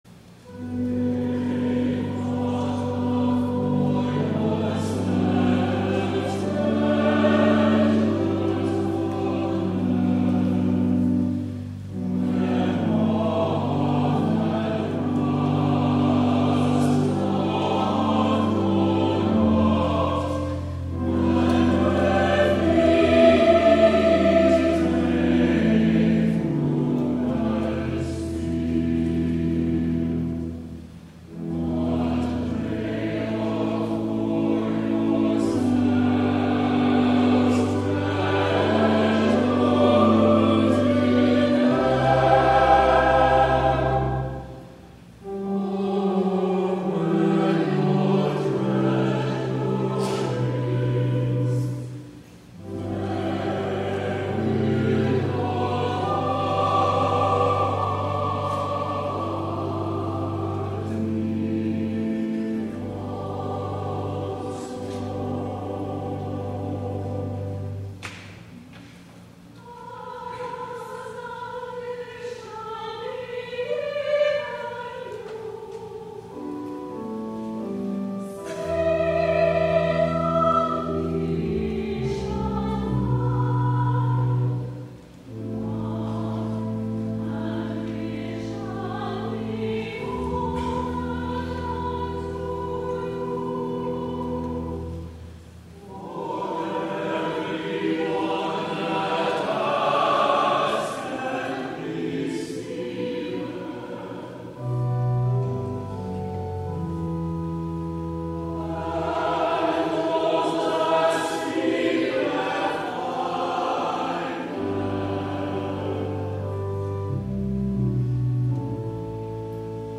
11 A.M. WORSHIP
THE ANTHEM